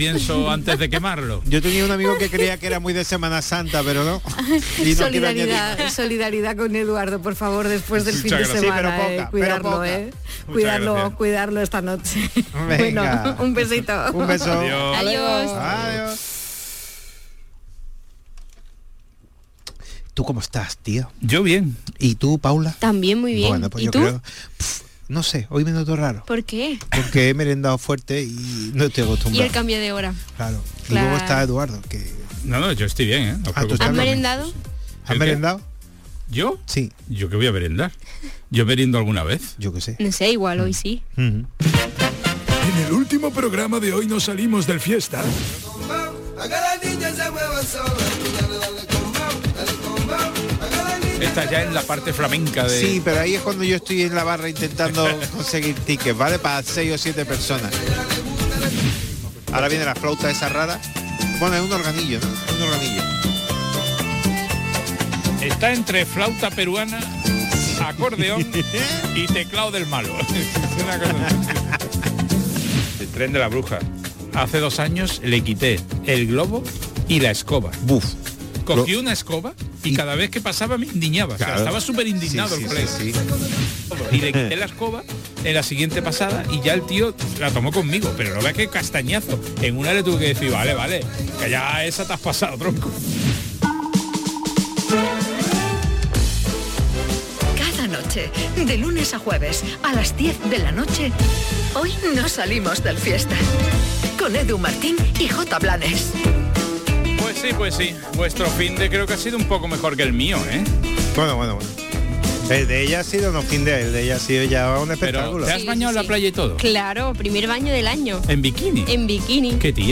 Buena música, humor y alguna que otra reflexión para organizarte tus cosas. Canal Fiesta te ofrece un programa nocturno de noticias y curiosidades muy loco. Un late radio show para que te quedes escuchando la radio hasta que te vayas a dormir.